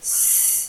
雅文檢測音
s_-15db.mp3